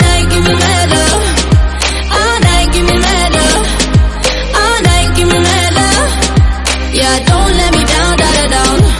2:49 *Reduced quality: yes *Source
" === This is a sound sample from a commercial recording.